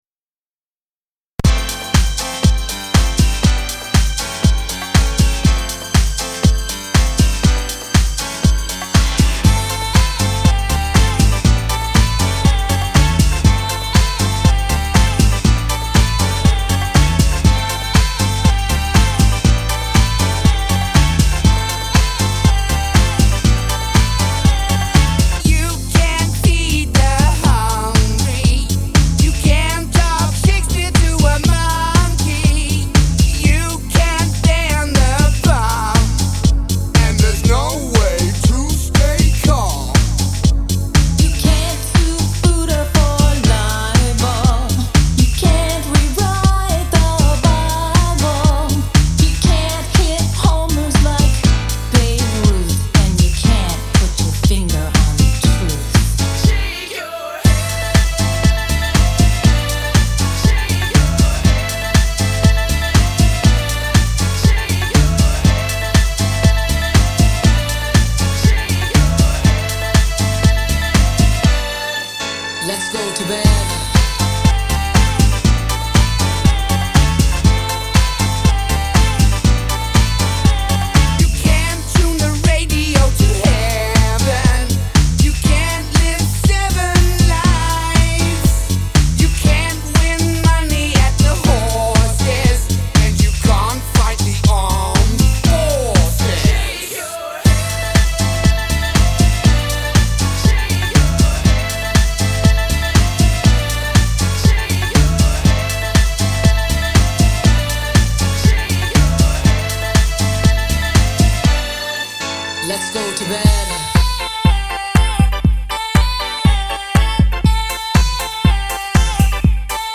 house remix